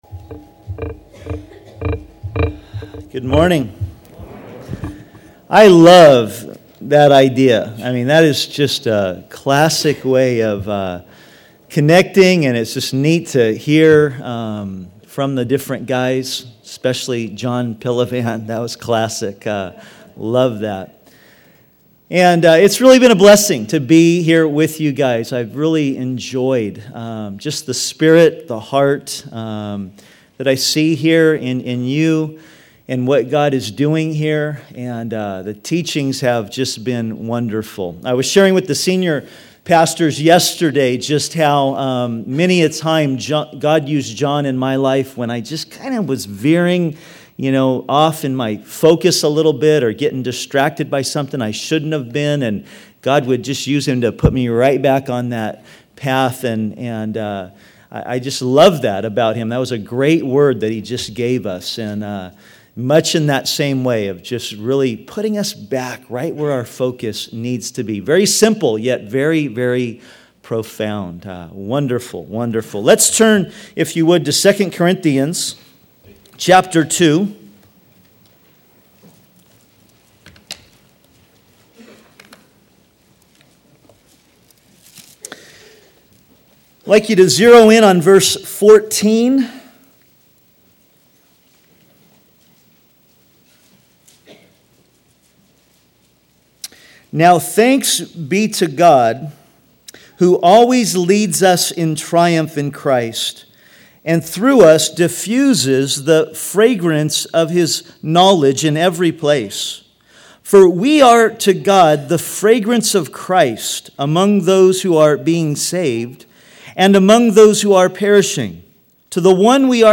2003 DSPC Conference: Pastors & Leaders Date